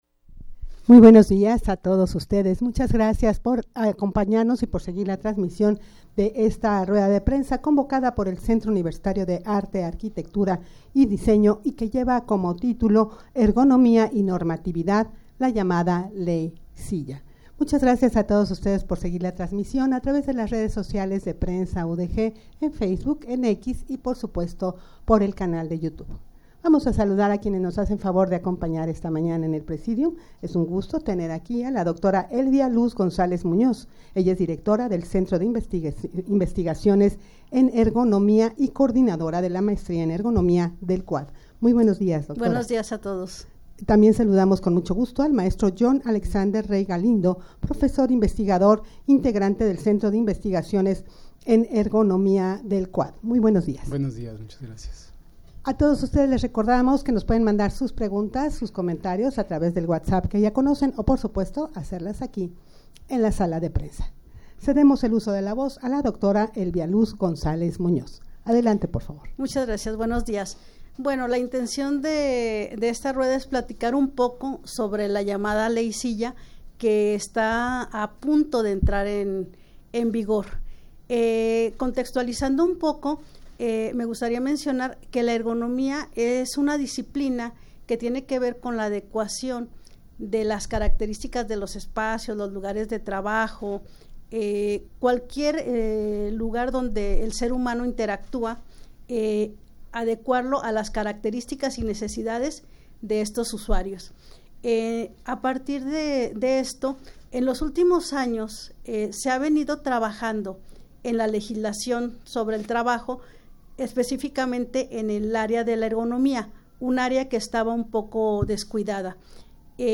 Audio de la Rueda de Prensa
rueda-de-prensa-ergonomia-y-normatividad-la-llamada-22ley-silla22.mp3